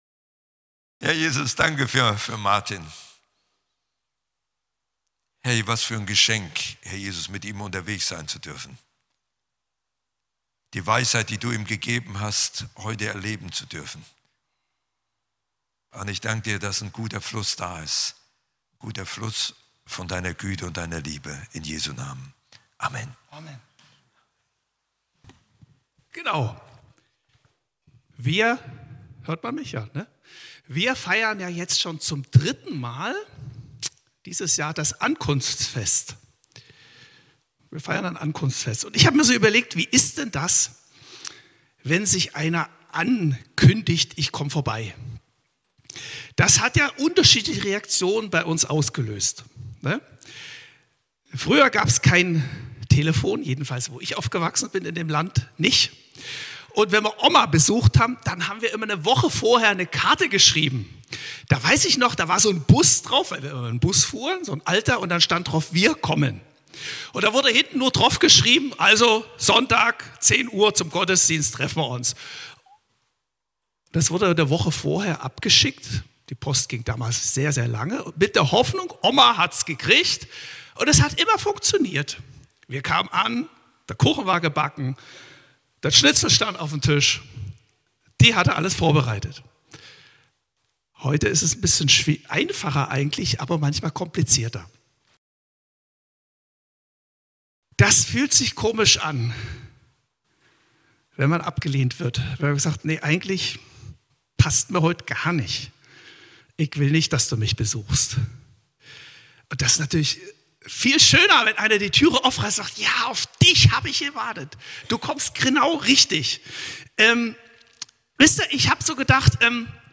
Gottesdienst vom 14.12.2025